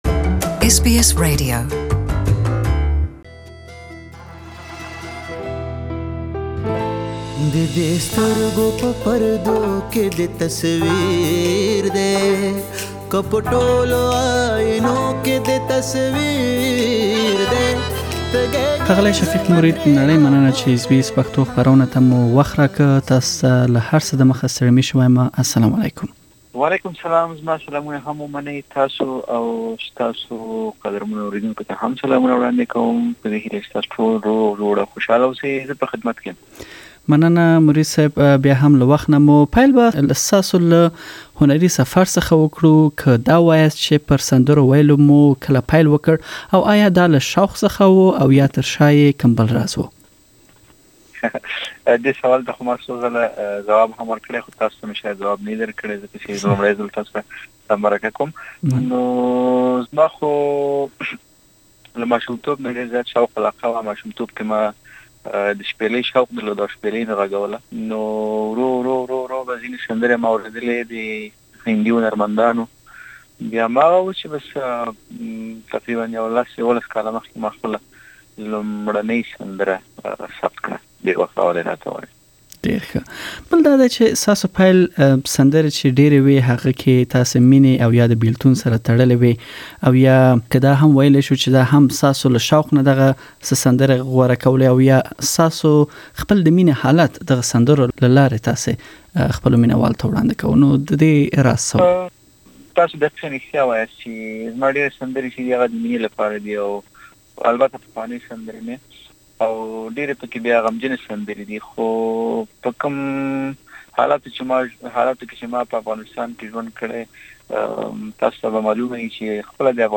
Please listen to the full interview in Pashto language and know more about Shafiq Mureed’s upcoming concerts in Australia.